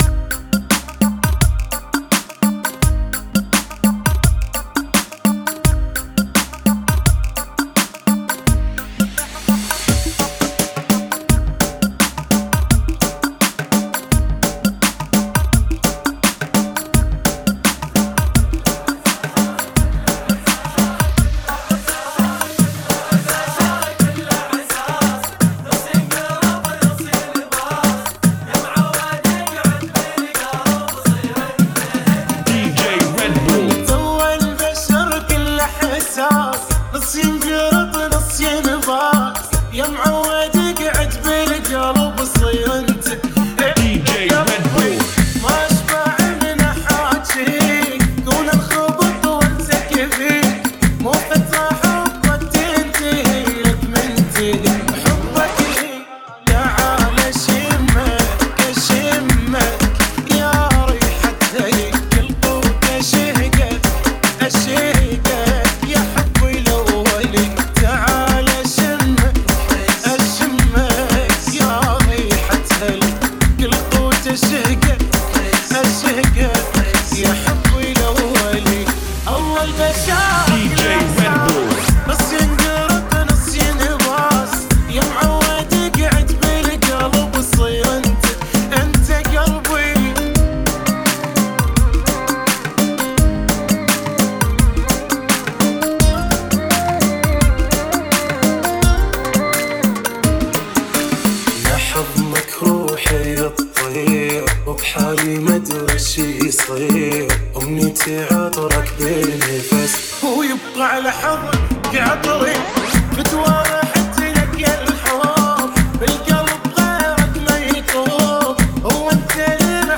[ 85 bpm ]